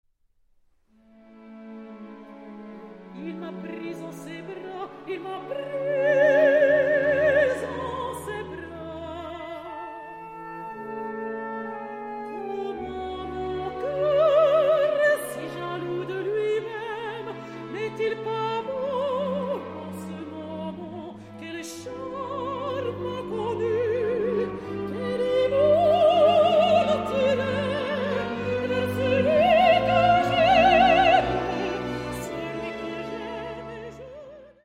Mélodies :